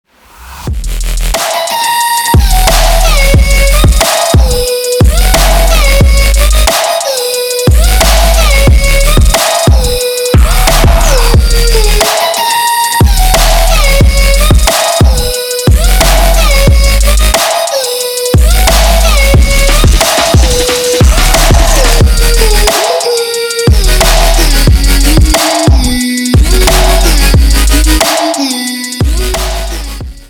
• Качество: 320, Stereo
громкие
Electronic
Стиль: Trap